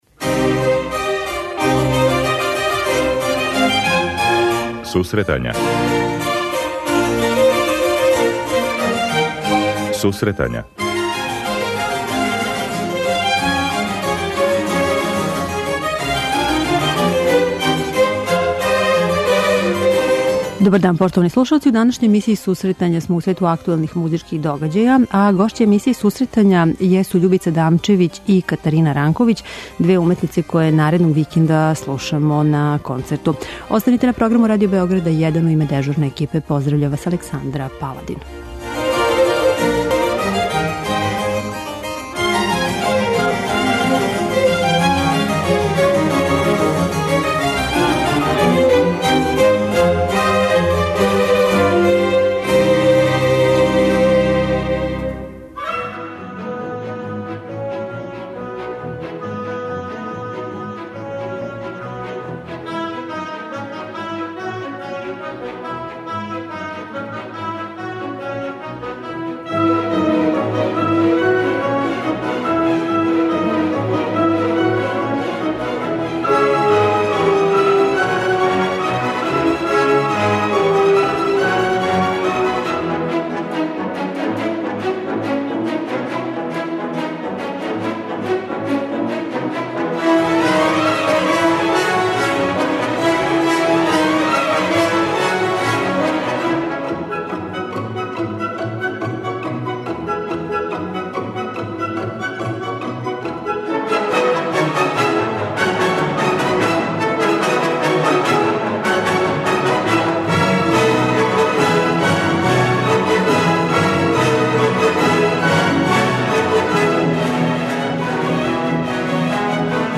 Данас смо у свету актуелних музичких догађаја. Гошће су нам две младе уметнице